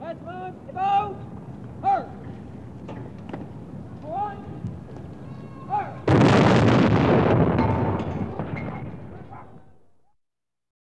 Реалистичные эффекты с хорошей детализацией низких частот.
Заряд пушки в такт голосу и мощный выстрел